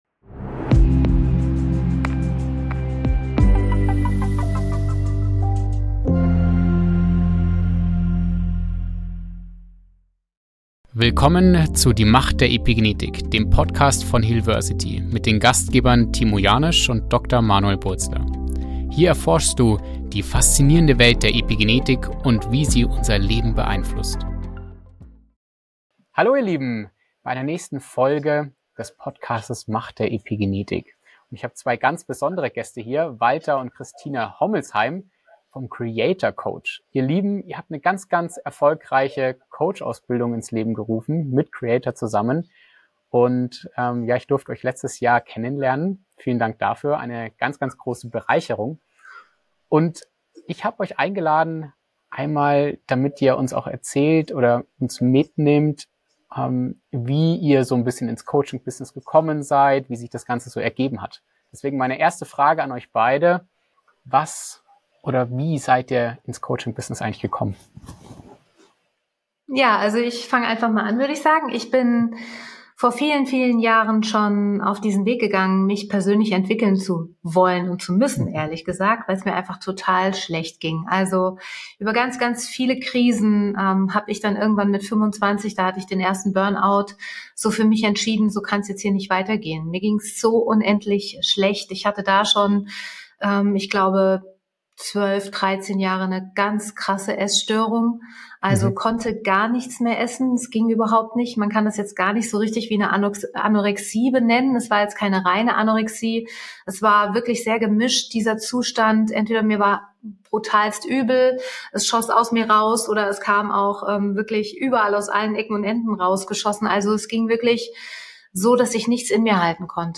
Das inspirierende Duo teilt ihre Reise in die Welt des Coachings und enthüllt, wie sie gemeinsam ihre Karriere aufgebaut haben.